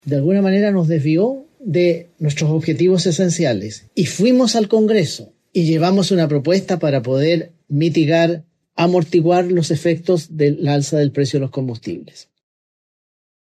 Con esto sobre la mesa, el Ejecutivo realizó un balance positivo de su gestión, el que fue abordado por el ministro de la Segpres, José García Ruminot.